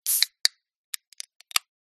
Звуки пива
Звук открытия алюминиевой банки пива